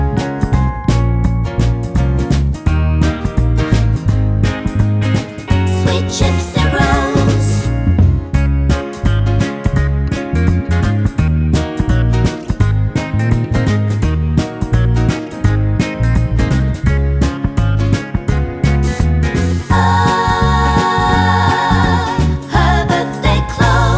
no Backing Vocals Medleys 2:53 Buy £1.50